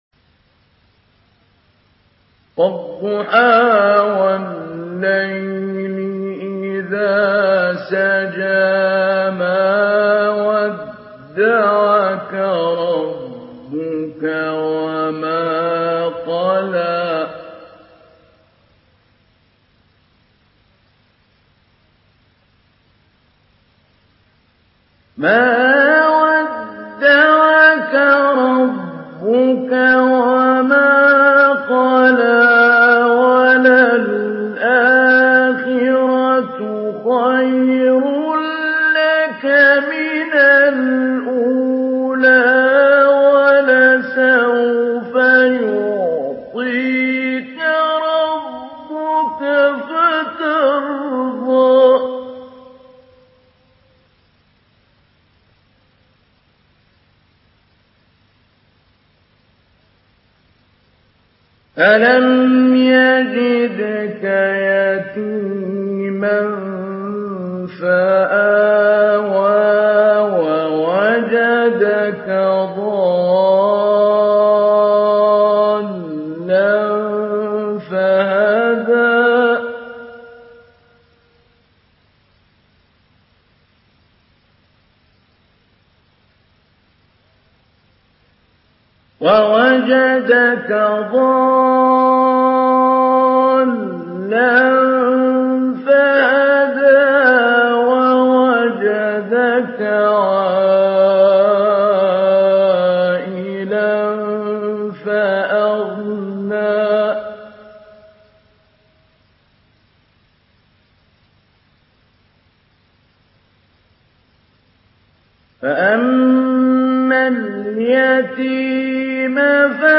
Surah Ad-Duhaa MP3 in the Voice of Mahmoud Ali Albanna Mujawwad in Hafs Narration
Mujawwad